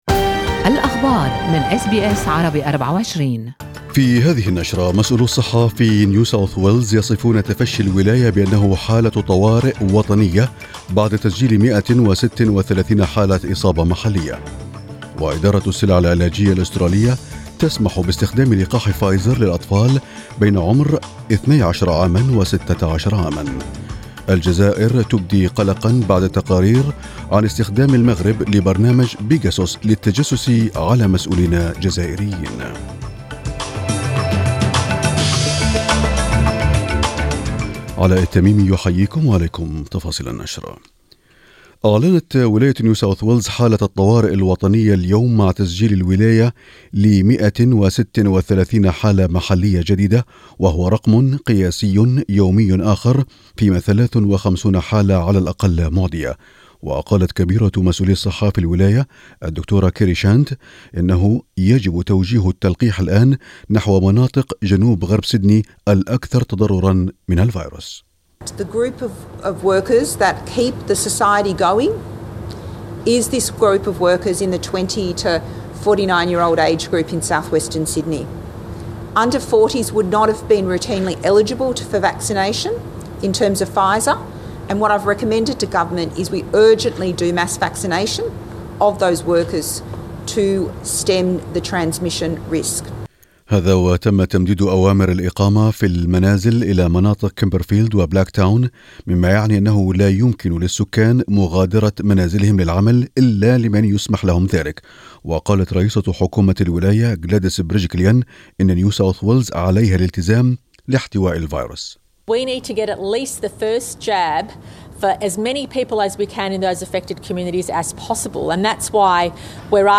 نشرة أخبار المساء 23/7/2021